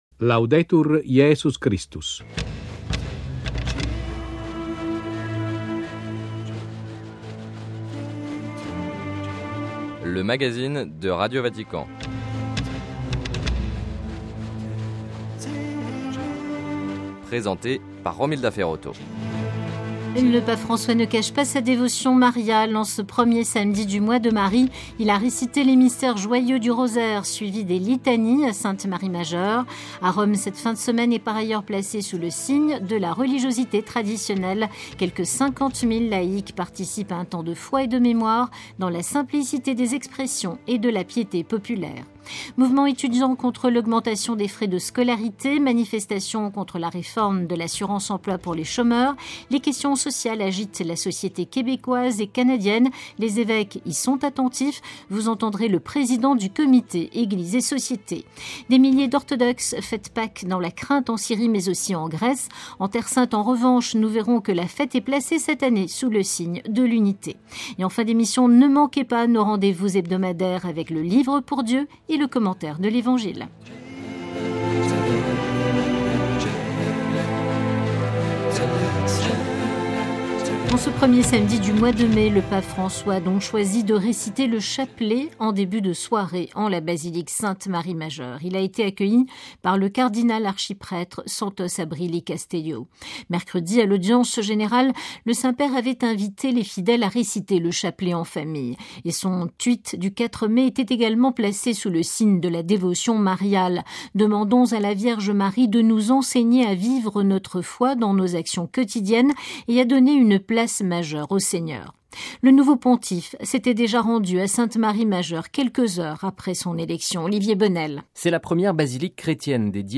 - Les évêques catholiques du Québec attentifs au réveil populaire sur les questions sociales : entretien avec Mgr Pierre Morissette.